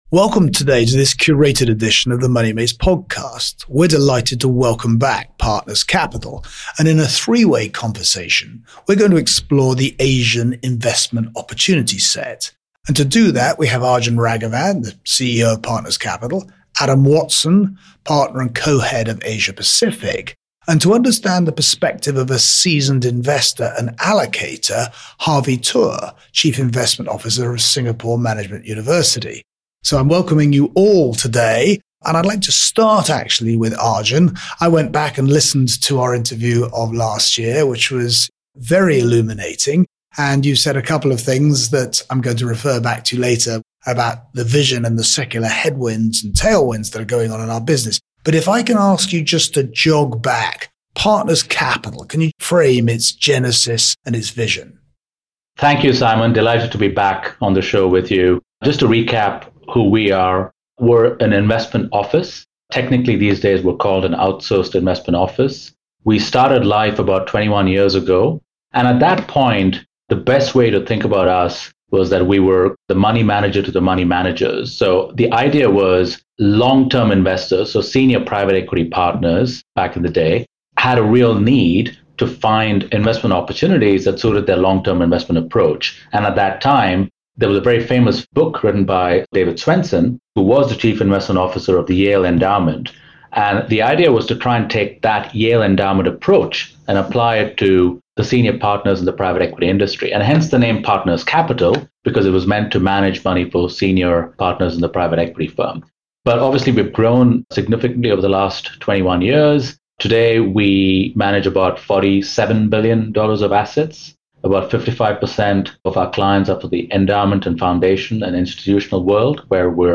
In this episode, we welcome back Partners Capital, and in a three way conversation, we explore the Asian investment opportunity set.